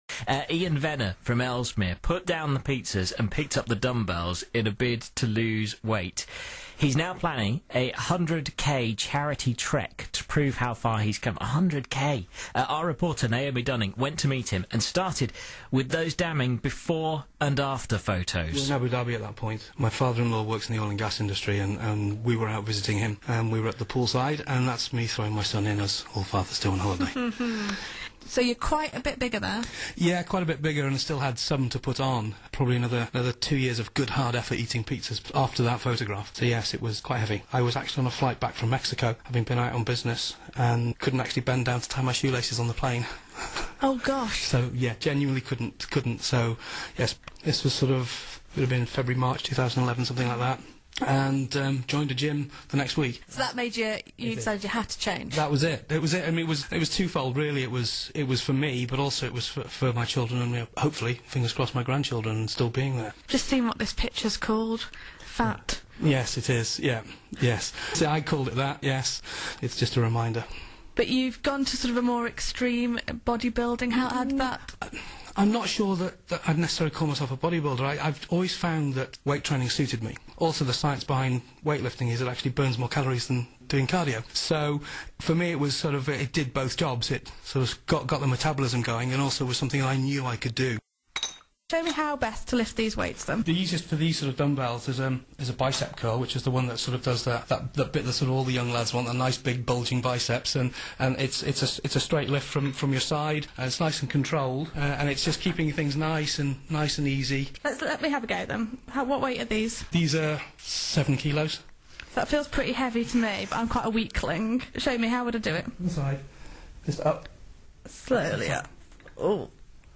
speaks on BBC Radio Shropshire about his personal mission to trek 100k for Blind Veterans UK.